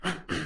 THMAdventure Sound " harrumph Loud00 - 声音 - 淘声网 - 免费音效素材资源|视频游戏配乐下载